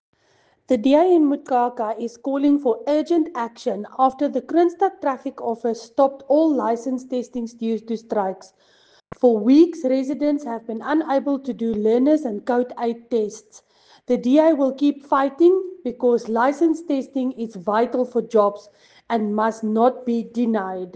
Afrikaans soundbites by Cllr Linda Louwrens and